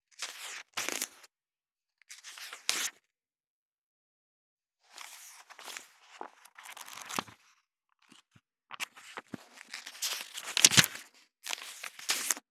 2.本ページめくり【無料効果音】
ASMR効果音本をめくる
ASMR